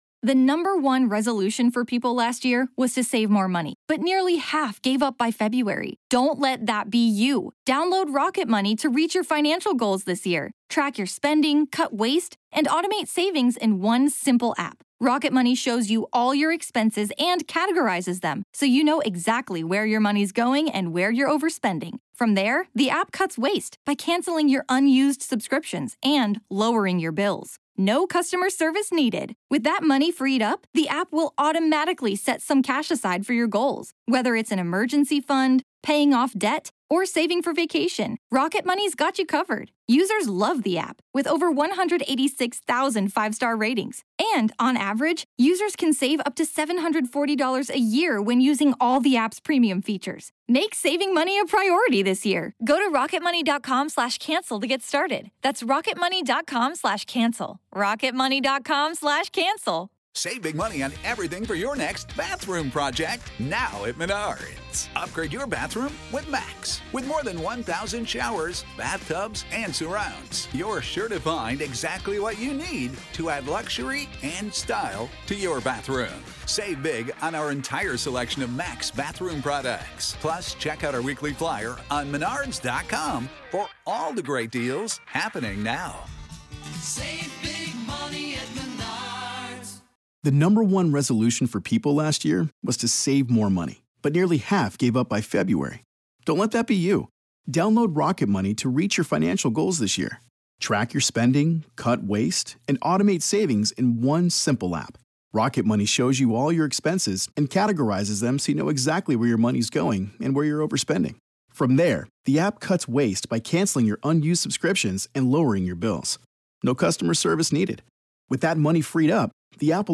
From shadow figures and strange sounds to the emotional weight that lingers in historic spaces, this conversation explores where preservation meets the paranormal—and how history and hauntings often occupy the same foundation.